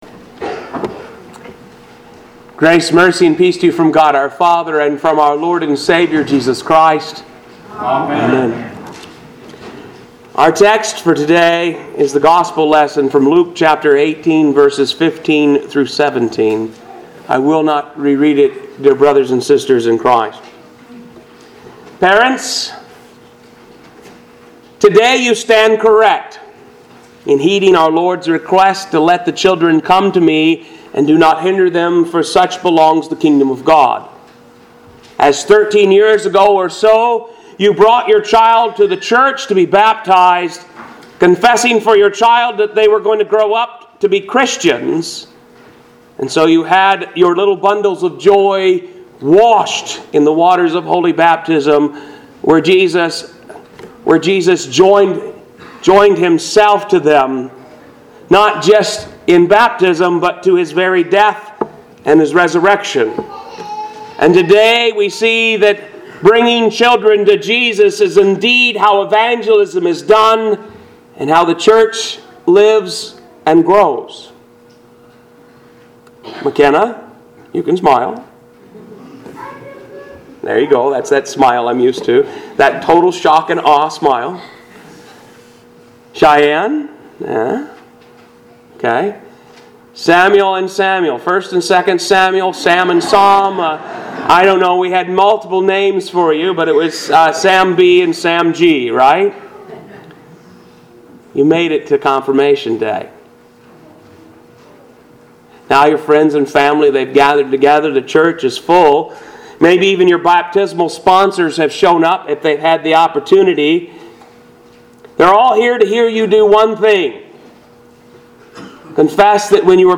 Lutheran Church - Sermons